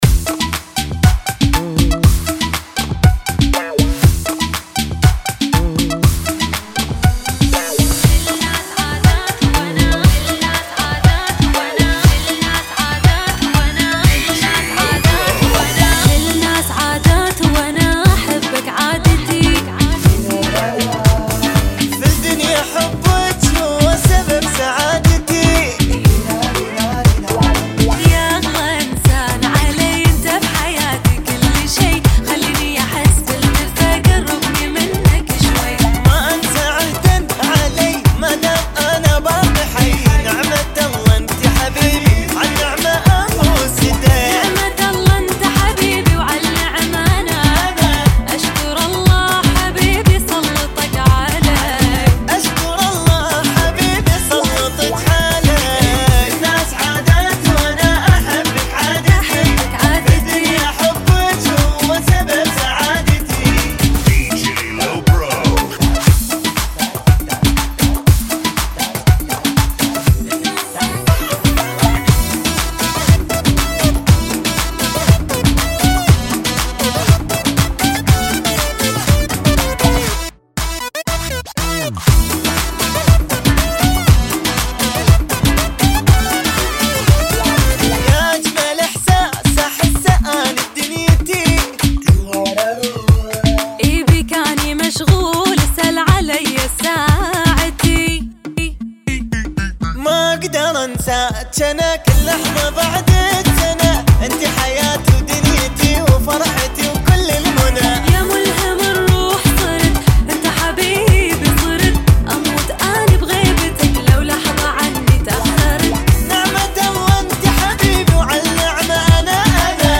[ 120 bpm ]